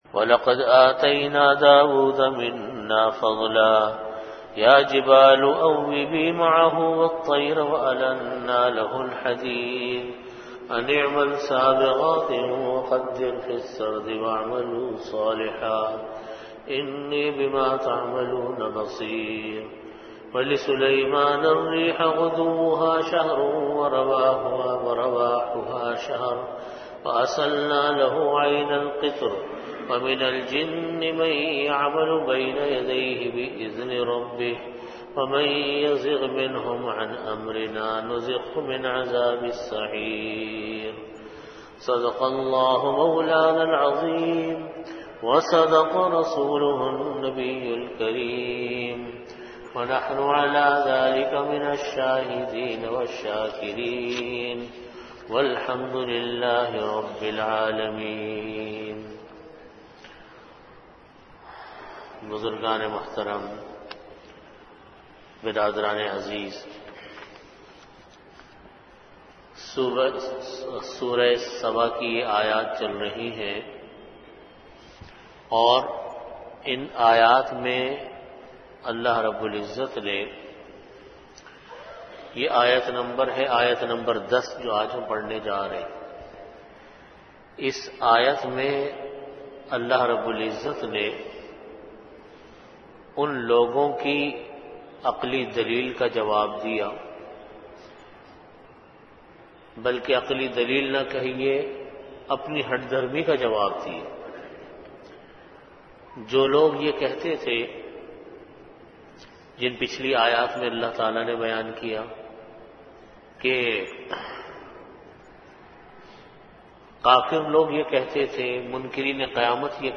Tafseer · Jamia Masjid Bait-ul-Mukkaram, Karachi